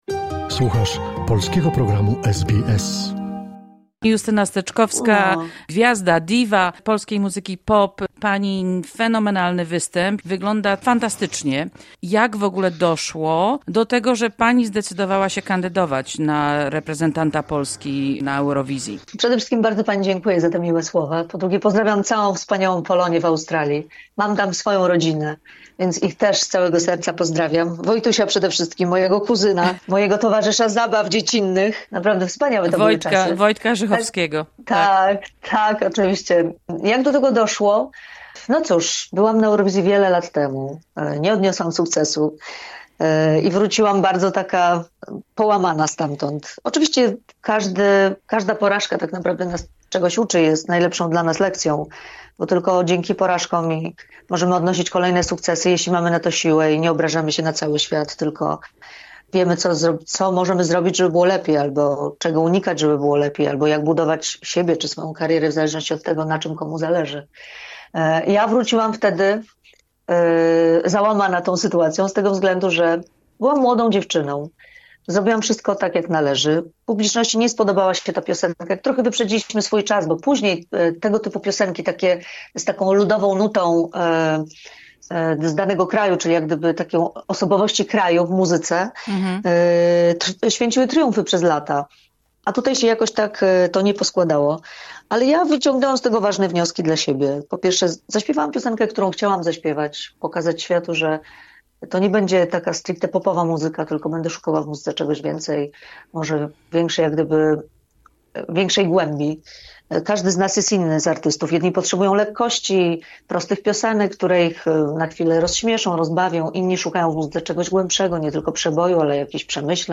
Gwiazda polskiej sceny muzycznej, diwa polskiego popu, wokalistka i instrumentalistka Justyna Steczkowska zakwalifikowała się do finału tegorocznego konkursu piosenki Eurowizja. 52-letnia artystka mówi SBS Polish o swoim drugim podejściu do Eurowizji, tym razem z utworem ‘Gaja’..